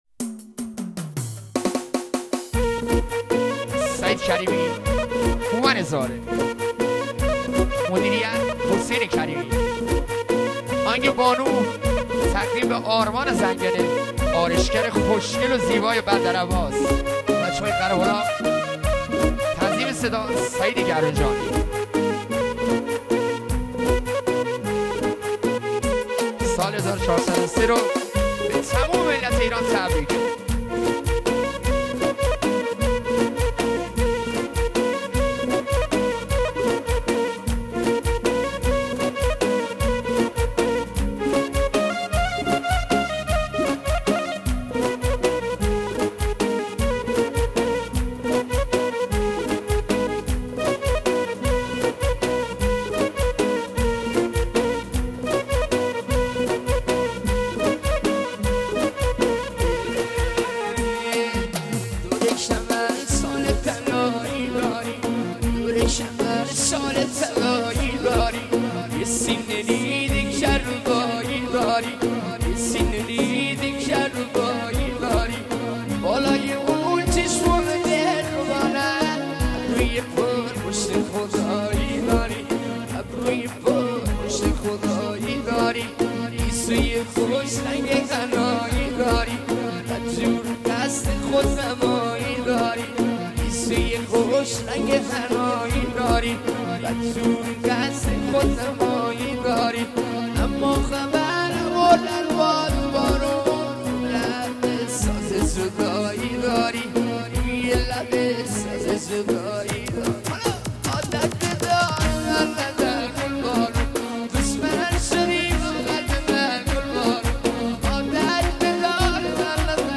به سبک خراسانی ولی با ورژن آروم شما هم قشنگ شده